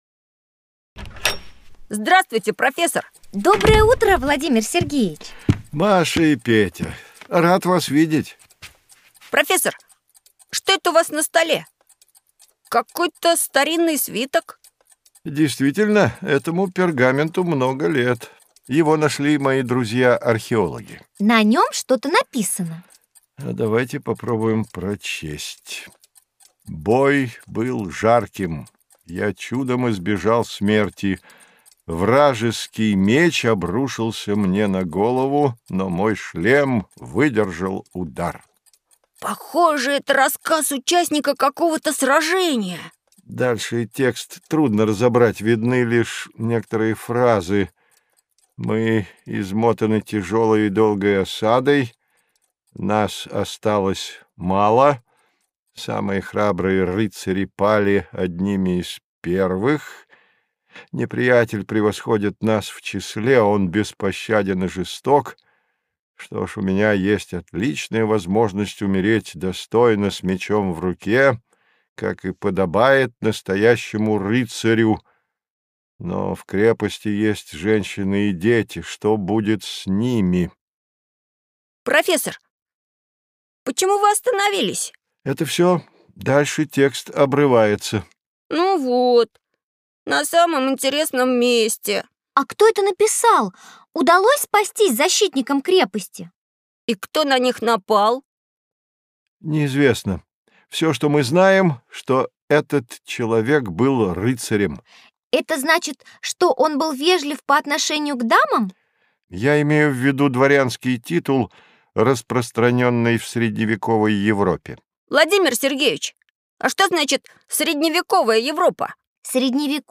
Аудиокнига История: Путешествие в Средневековье. Замки, рыцари, предания | Библиотека аудиокниг